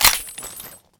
Index of /server/sound/weapons/cw_mr96